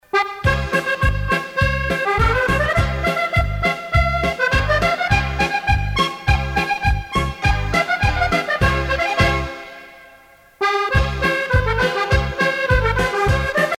danse : quadrille
Pièce musicale éditée